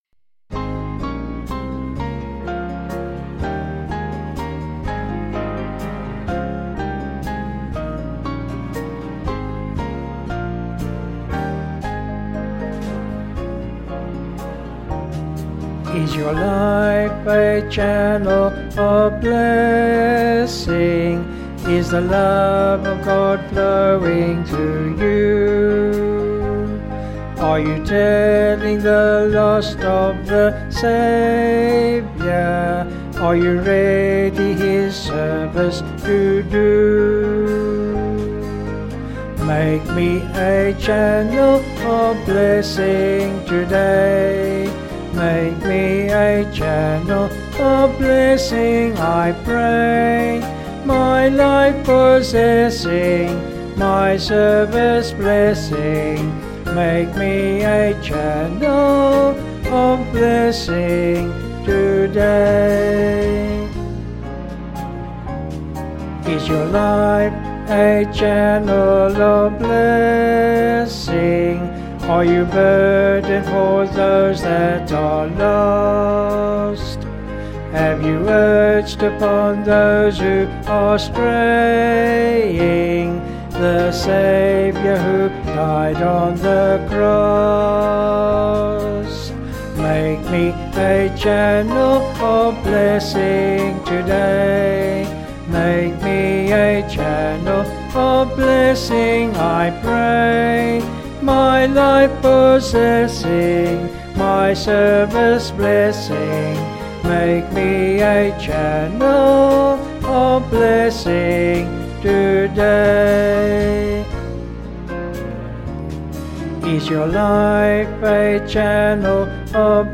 Vocals and Band   264.4kb Sung Lyrics